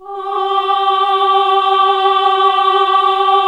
AAH G2 -L.wav